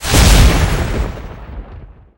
Magic_Spell07.wav